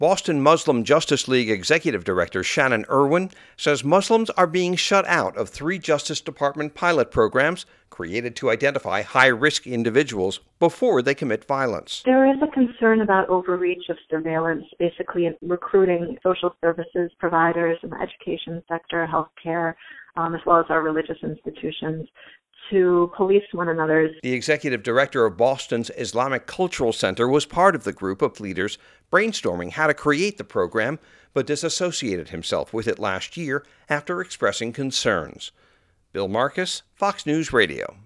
Muslim groups nationally call for greater transparency in anti-terrorism program